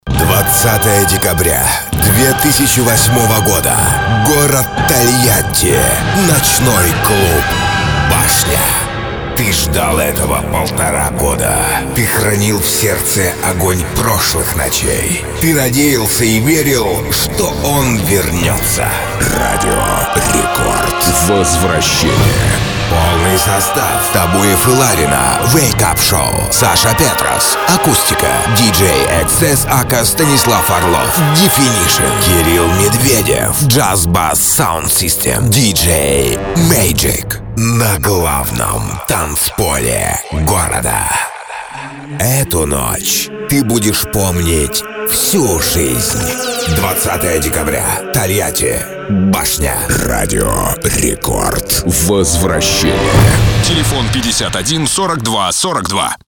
Муж, Рекламный ролик/Средний